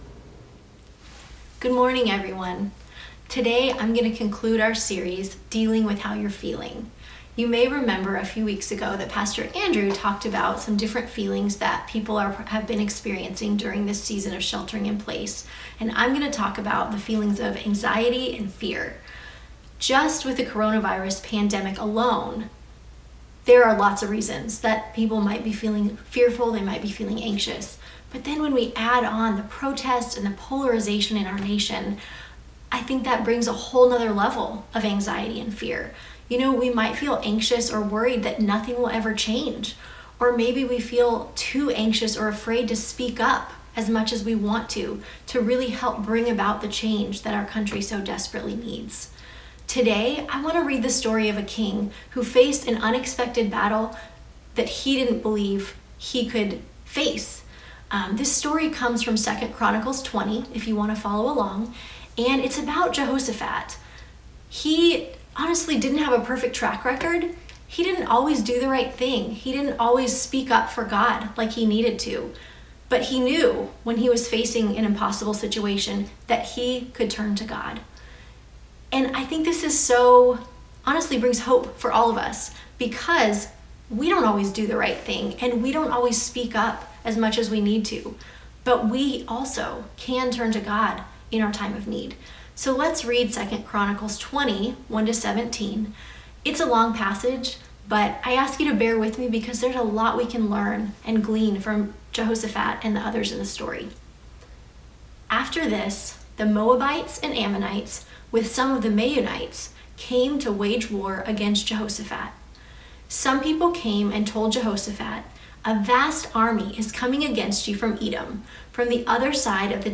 June 7, 2020 Sunday Message